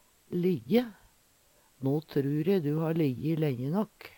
DIALEKTORD PÅ NORMERT NORSK lijje ligge Infinitiv Presens Preteritum Perfektum lijje ligg låg lijji Eksempel på bruk No trur e du ha lijji lenje nåkk!